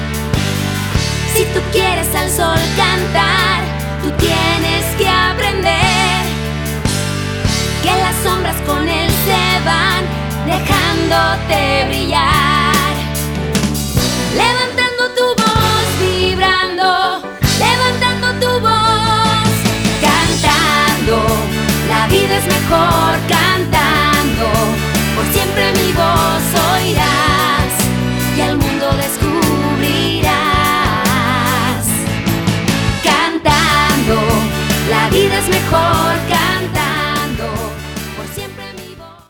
In 2007, she released another new children's album.